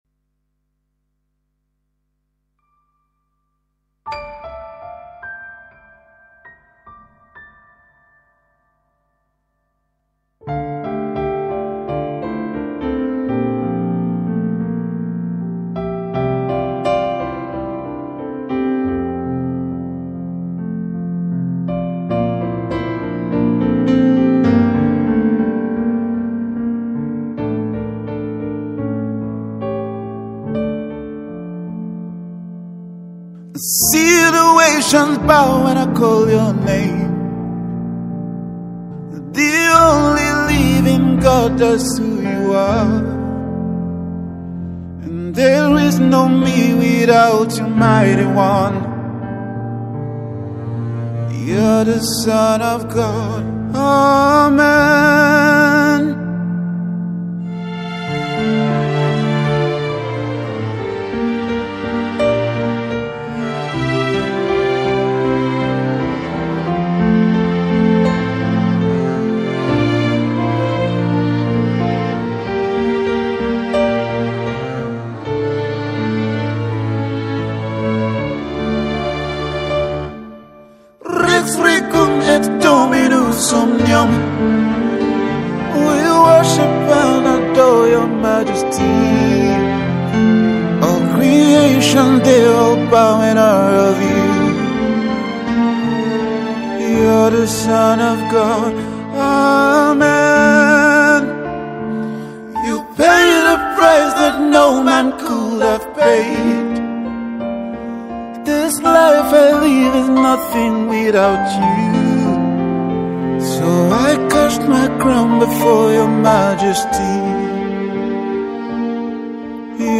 Gospel Act and songwriter